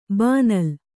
♪ bānal